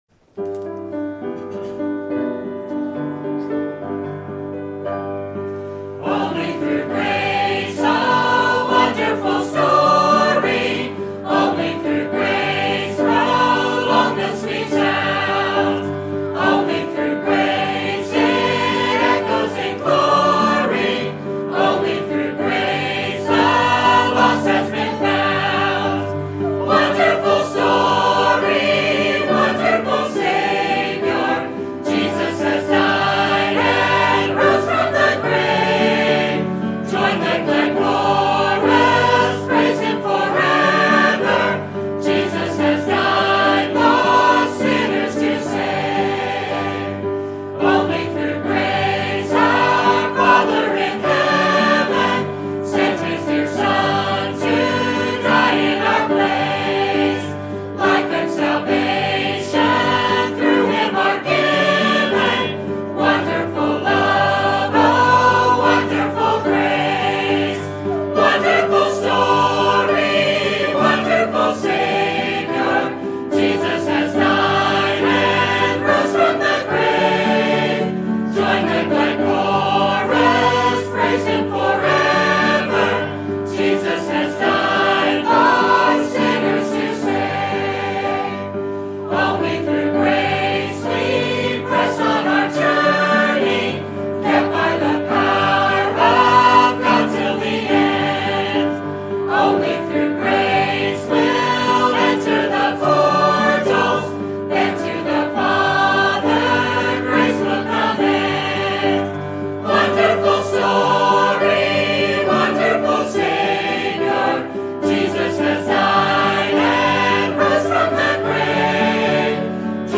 Sermon Topic: Life of Kings and Prophets Sermon Type: Series Sermon Audio: Sermon download: Download (32.82 MB) Sermon Tags: 1 Samuel Kings Prophets Obedience